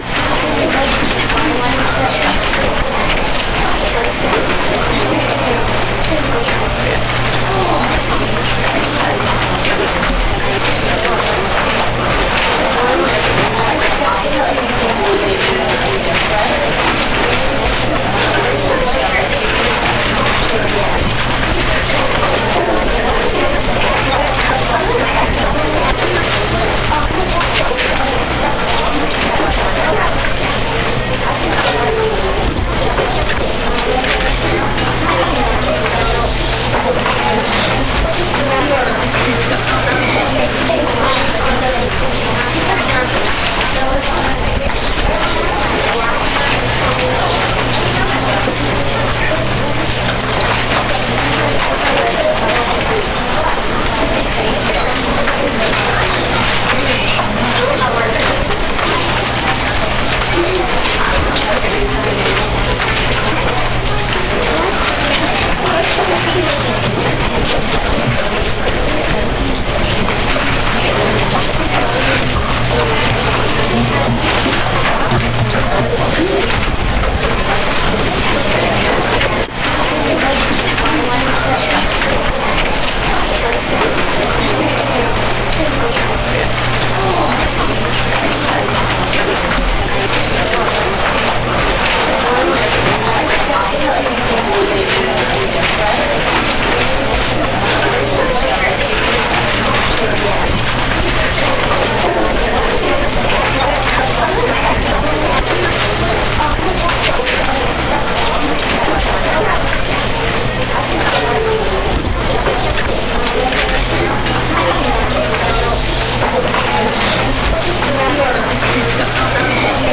Simulated Classroom Noise - The Moog Center for Deaf Education
Simulated Classroom Noise is a form of background noise developed to simulate the noise in a typical classroom.  This noise is recommended when targeting the development of auditory comprehension skills in background noise and is intended for use with the Teacher Assessment of Auditory Skills (TAAS).
ClassroomNoise.mp3